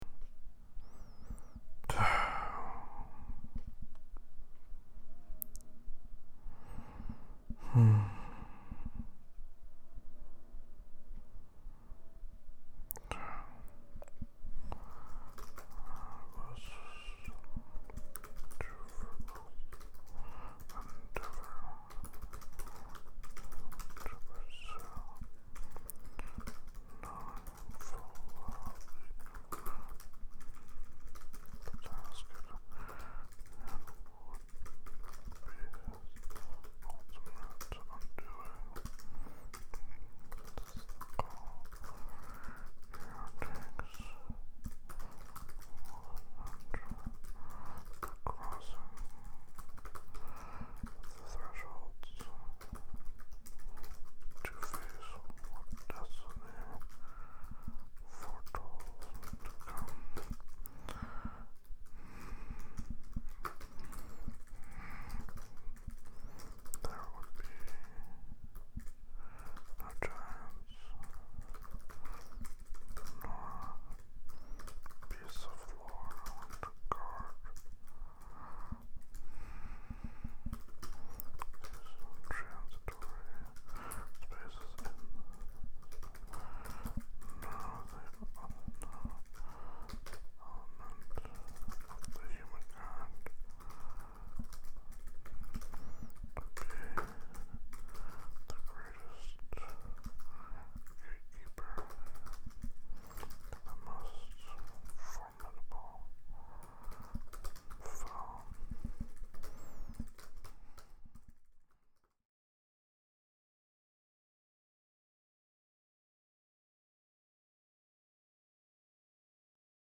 You are listening in as I work on a section of my book doing a re-write.